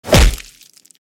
hit_sound.mp3